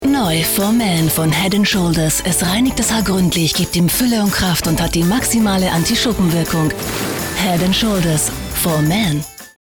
deutsche Sprecherin.
Sprechprobe: Werbung (Muttersprache):
german female voice over artist, dubbing